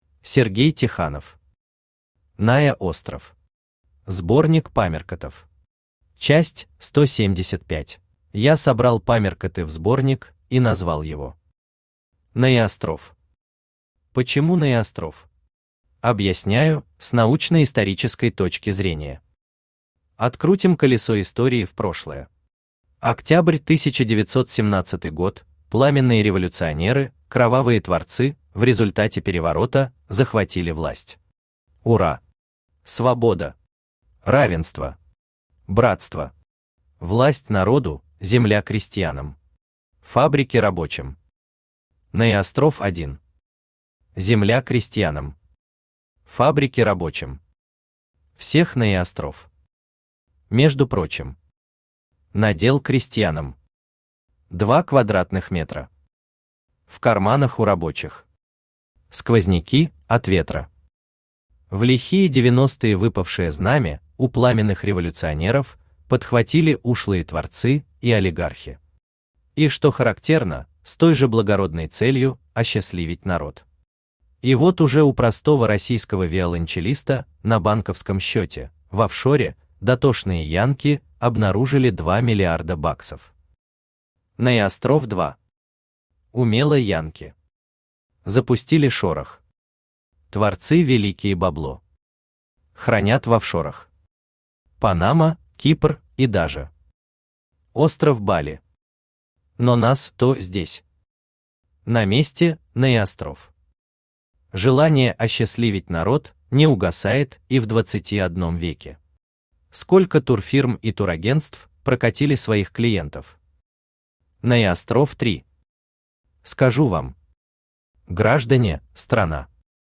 Аудиокнига НаеОстров. Сборник памяркотов. Часть 175 | Библиотека аудиокниг
Читает аудиокнигу Авточтец ЛитРес.